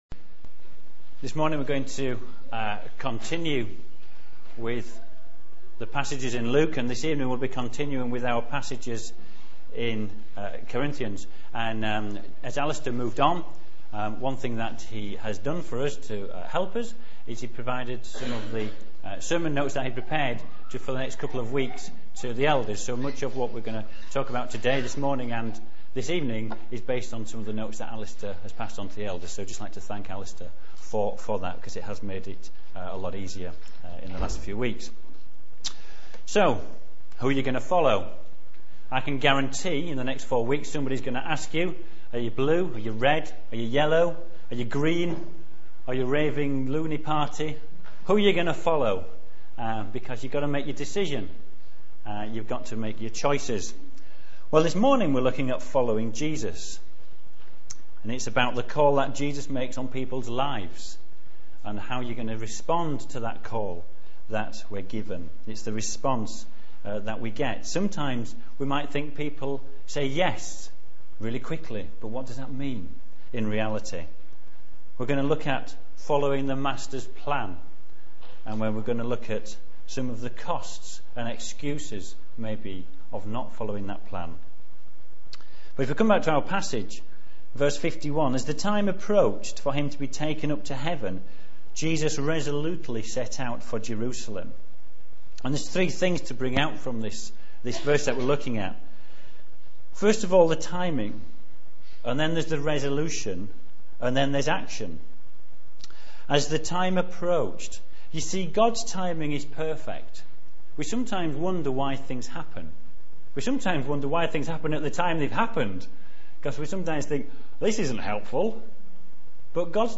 Latest Episode Luke 9:51-62 Download the latest episode Note: in some browsers you may have to wait for the whole file to download before autoplay will launch. A series of sermons from the Gospel of Luke A series of sermons on the Gospel of Luke preached at Emmanuel Church, Northwich.